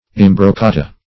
Search Result for " imbroccata" : The Collaborative International Dictionary of English v.0.48: Imbrocata \Im`bro*ca"ta\, Imbroccata \Im`broc*ca"ta\, n. [It. imbroccata.] A hit or thrust.